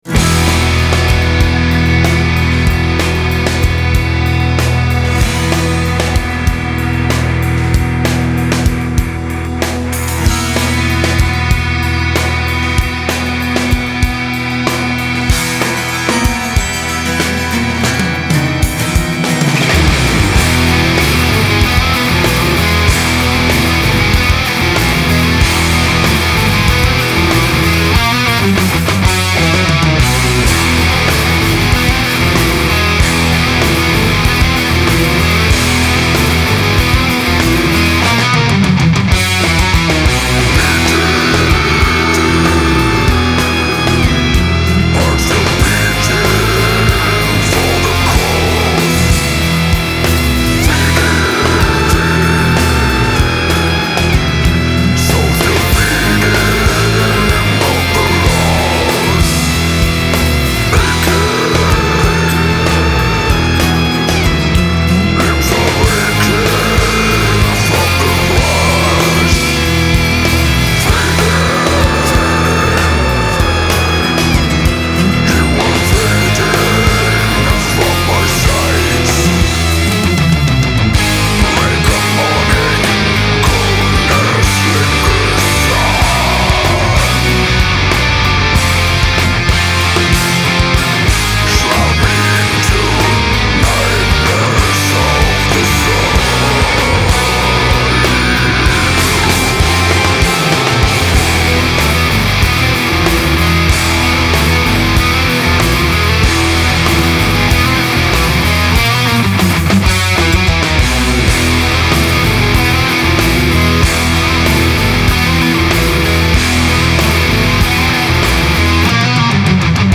genre: progressive metal,death metal